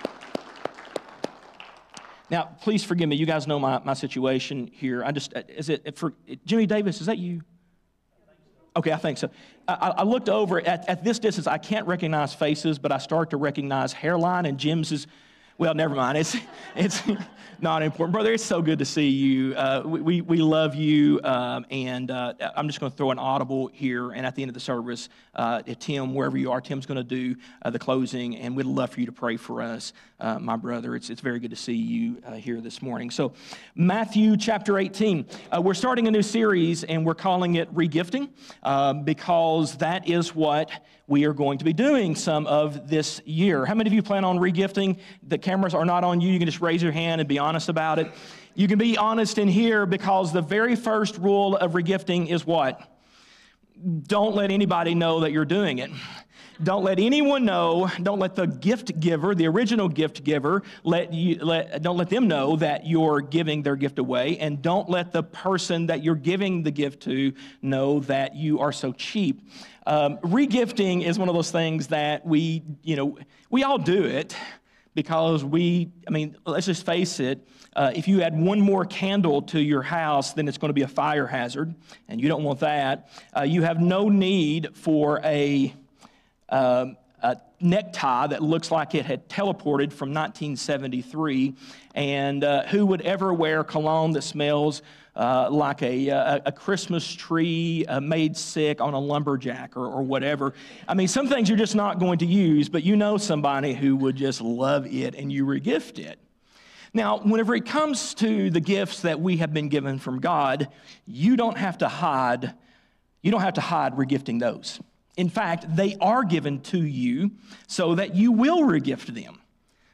Sermons | Christiansburg Baptist Church | Christiansburg, VA